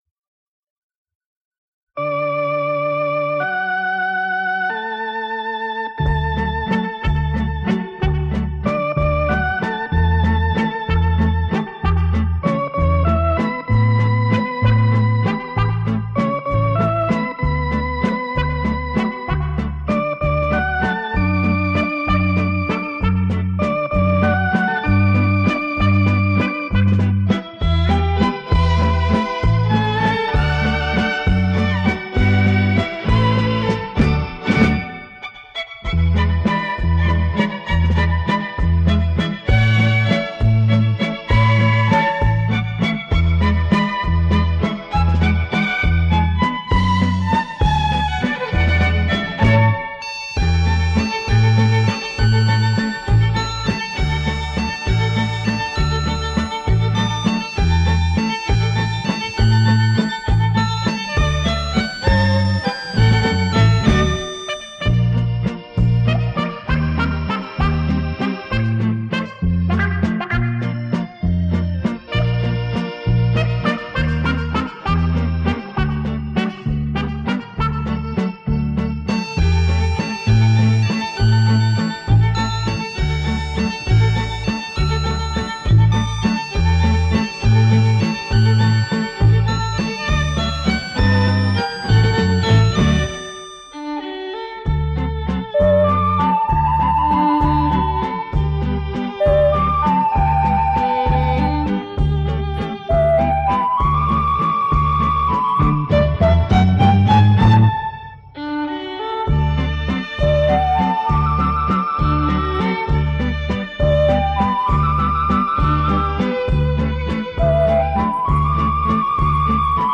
（磁带版录制）